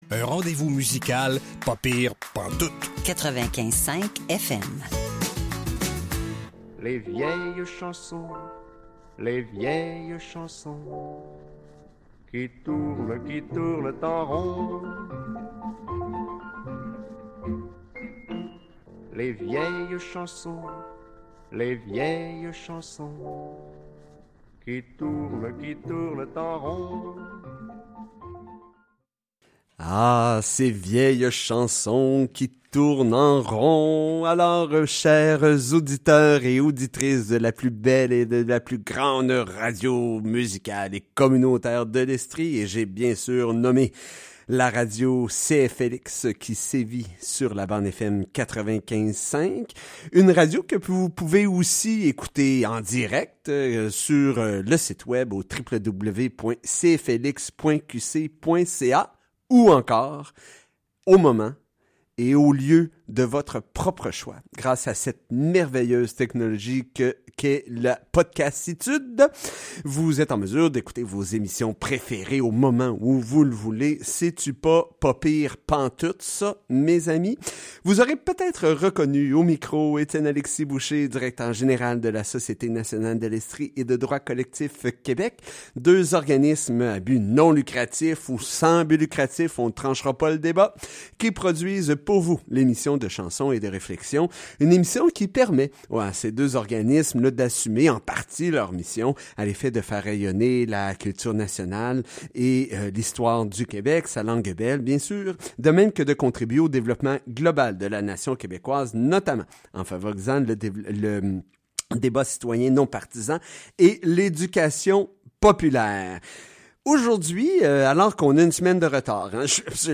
Entrevue avec Daniel Turp, président de Droits collectifs Québec, sur la situation des droits linguistiques au Québec et au Canada.